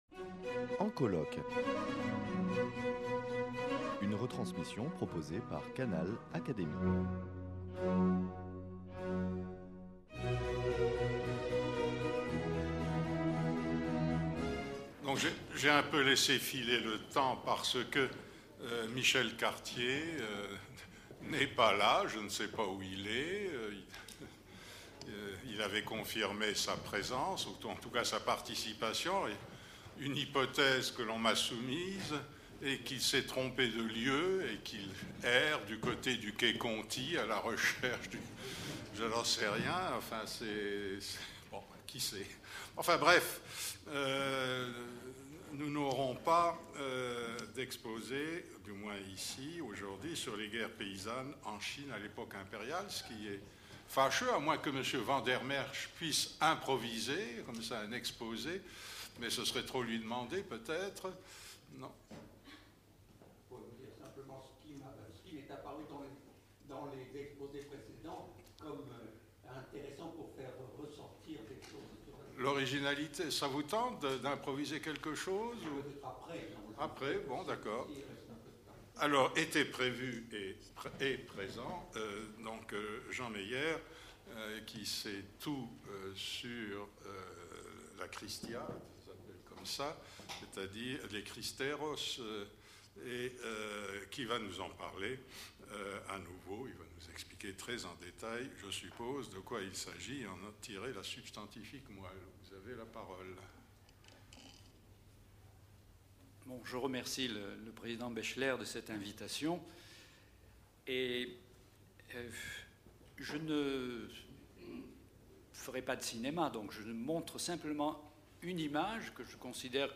Retransmission du colloque international « La guerre civile » - partie 4